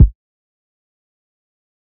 Tm8_Kick1.wav